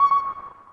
chat-inbound_GSM.wav